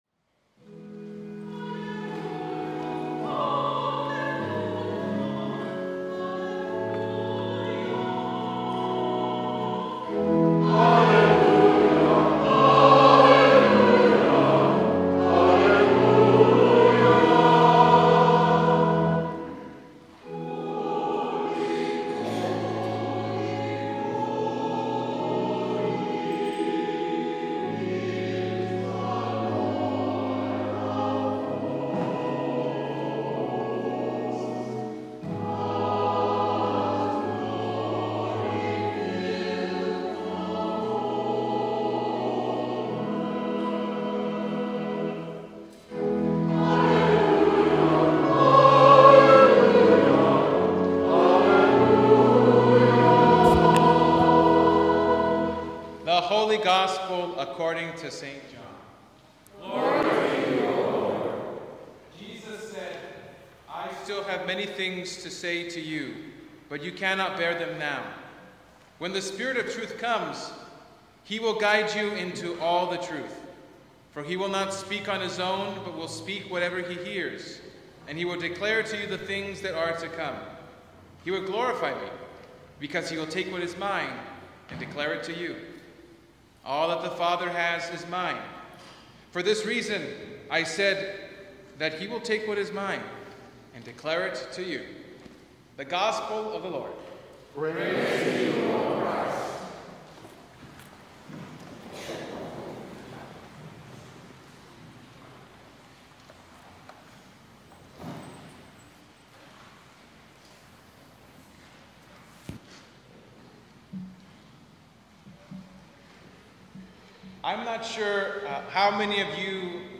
Sermon from Sunday